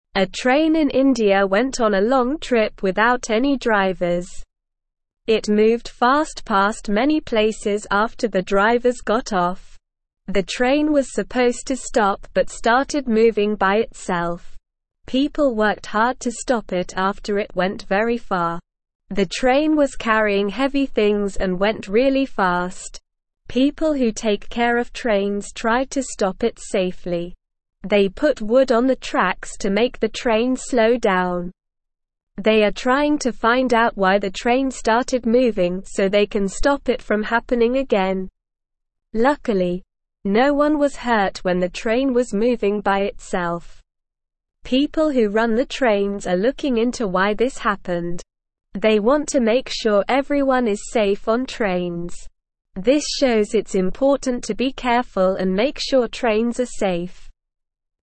Slow
English-Newsroom-Lower-Intermediate-SLOW-Reading-Runaway-Train-in-India-Goes-on-Long-Trip.mp3